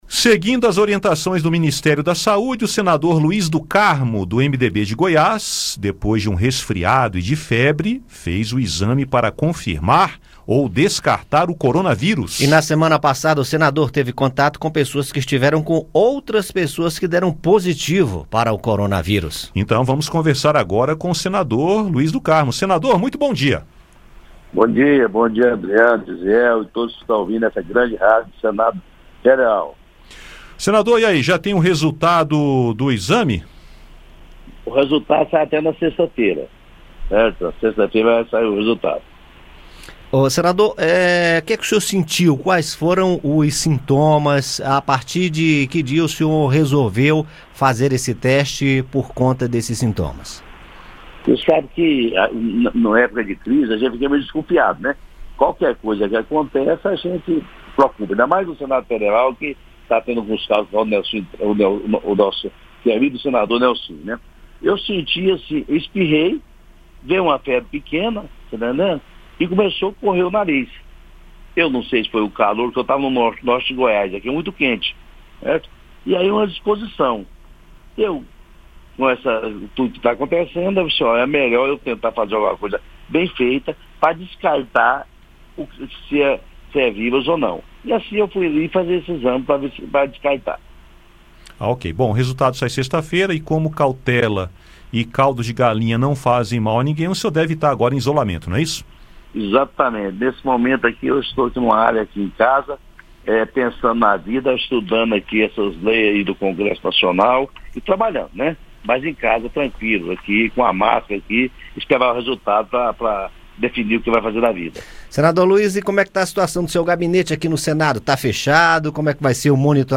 Nesta terça-feira (17), conversamos com o senador Luiz do Carmo (MDB-GO), que fez teste para verificar possível contaminação pelo coronavírus causador da pandemia covid-19. Ouça o áudio com a entrevista.